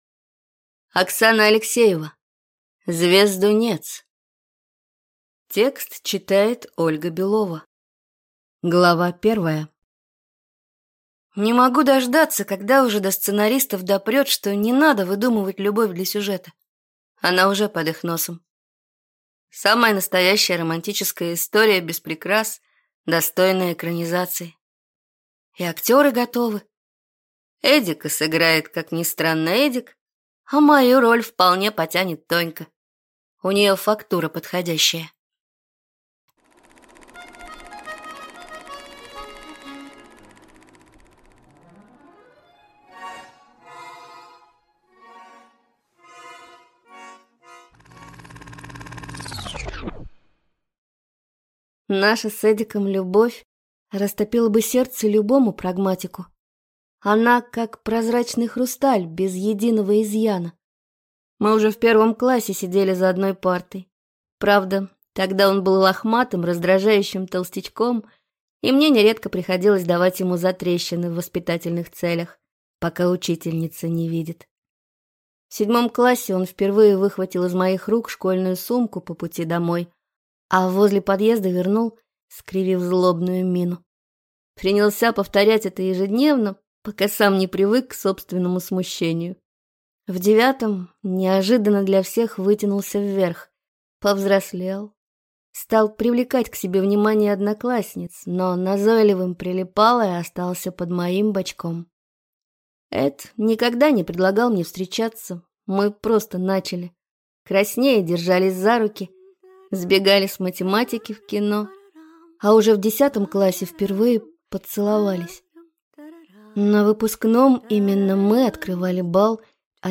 Аудиокнига Звездунец | Библиотека аудиокниг